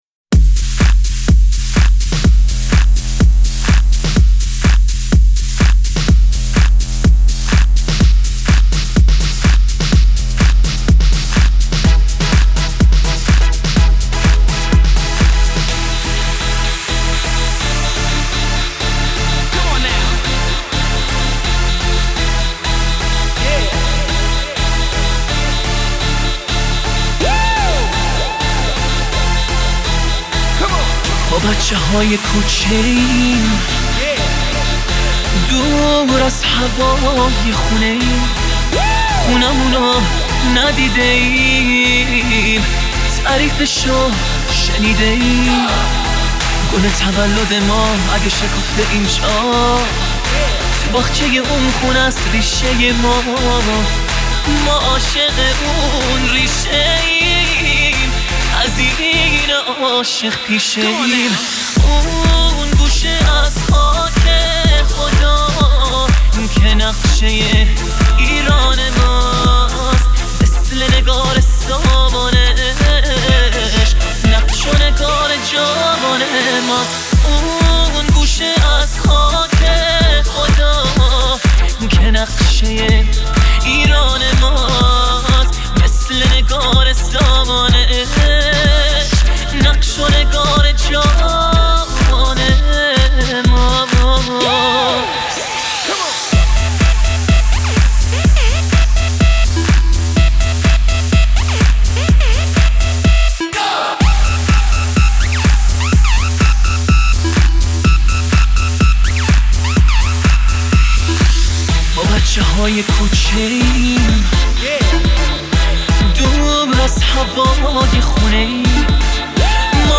Party Rock Remix